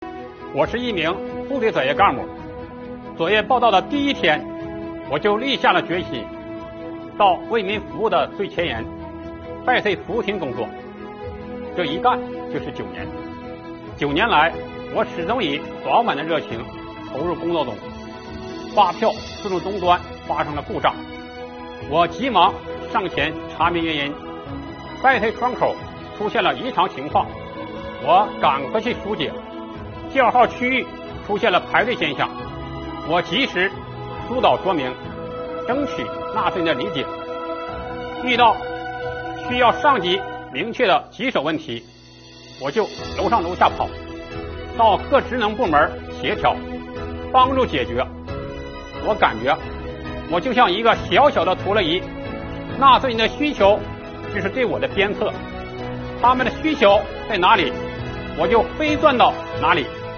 微宣讲共设100期，改变台上一人讲、台下大家听的方式，由身边人讲述自身感悟，引发大家共鸣。